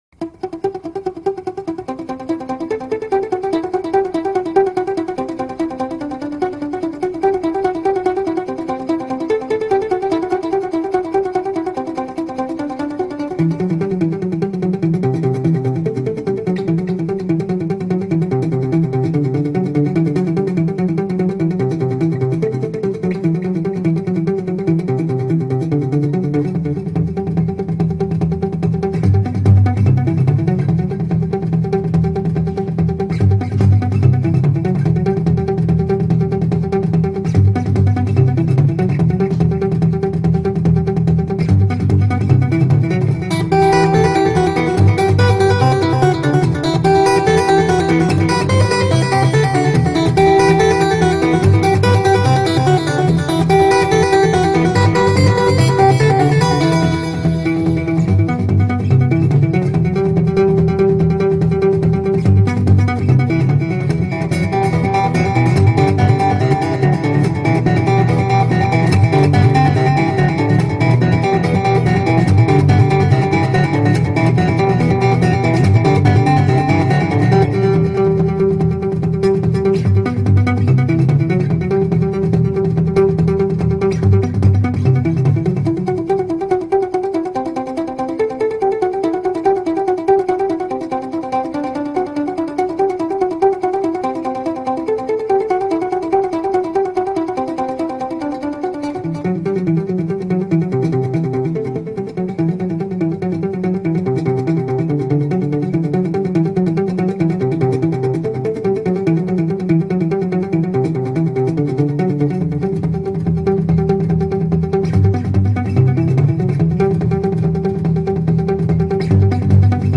igraet na gitare trans